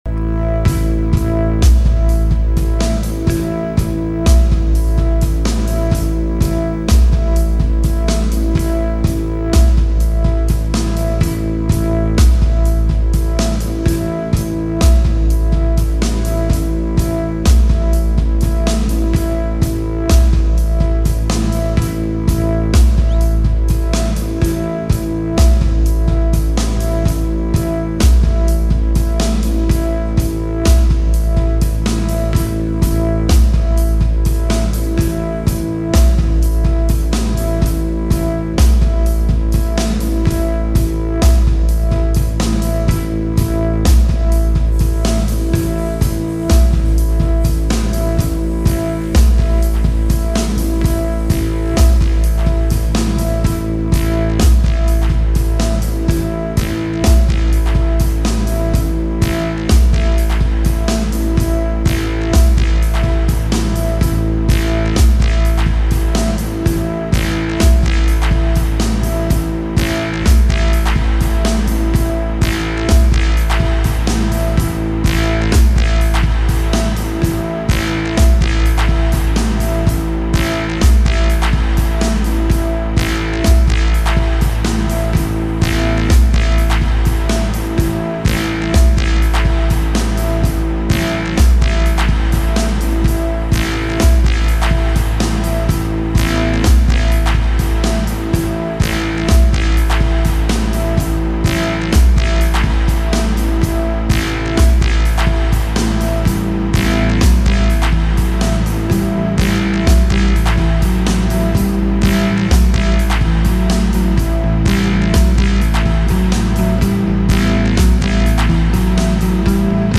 guitar, vocals, electronics
bass guitar
drums